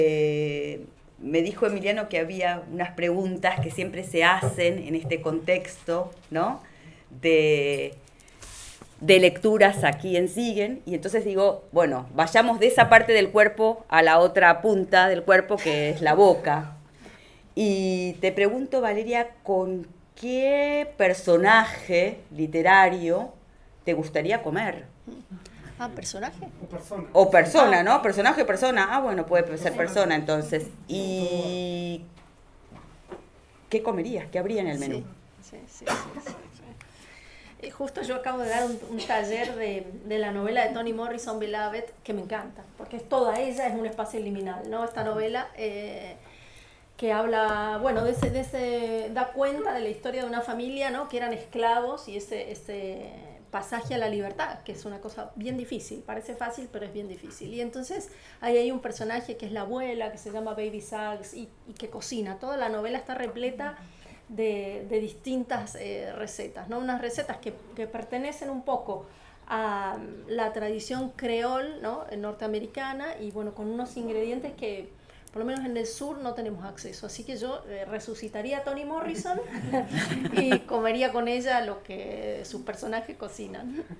Encuentros literarios en Siegen
Entrevista